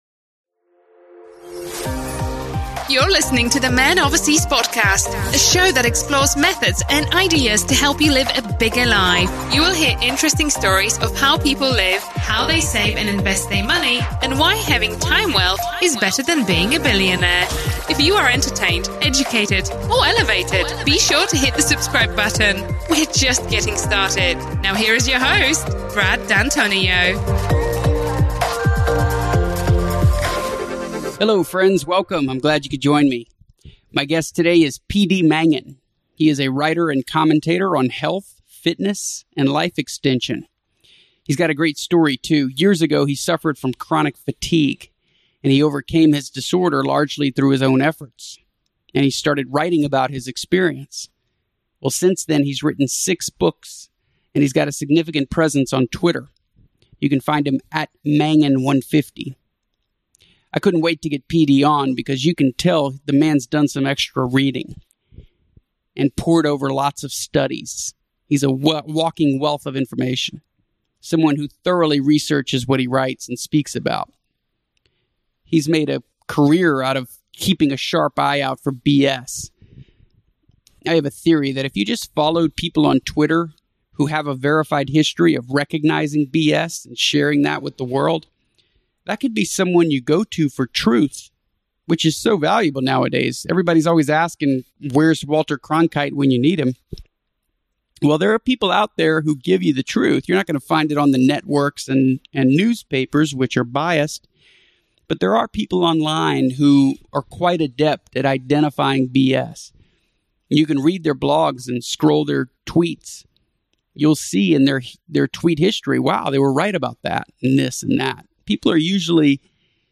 Enlightening discussion with a wicked smart & insightful guest—hope you get as much out of this chat as I did.